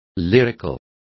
Complete with pronunciation of the translation of lyrical.